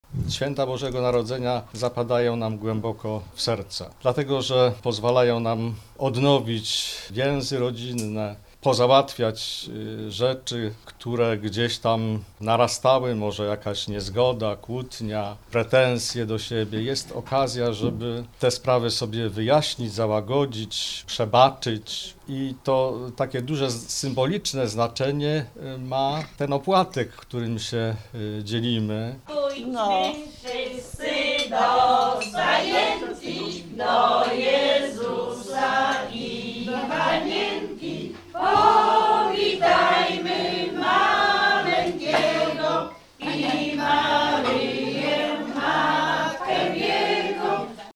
Spotkanie opłatkowe seniorów
Seniorzy z gorzowskiego klubu „Aktywna jesień” spotkali się na tradycyjnym „opłatku”. Były świąteczne dania, śpiewanie kolęd, składanie życzeń i drobne upominki.